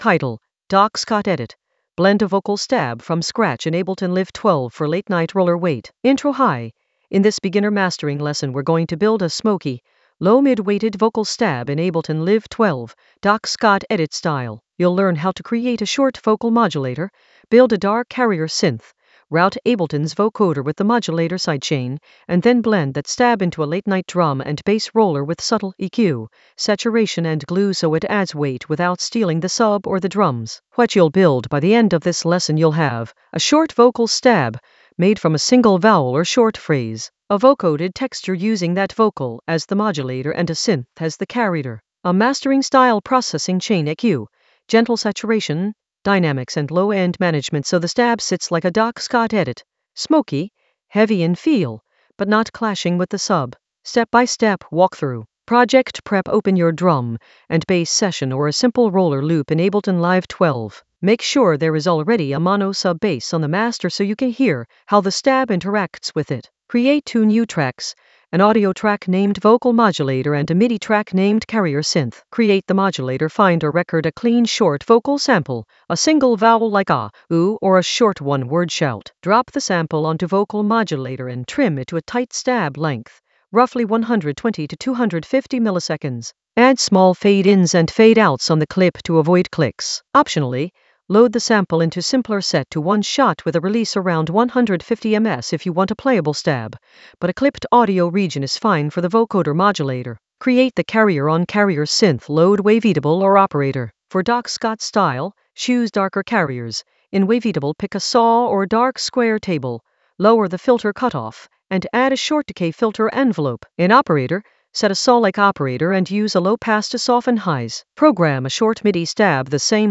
An AI-generated beginner Ableton lesson focused on Doc Scott edit: blend a vocal stab from scratch in Ableton Live 12 for late-night roller weight in the Mastering area of drum and bass production.
Narrated lesson audio
The voice track includes the tutorial plus extra teacher commentary.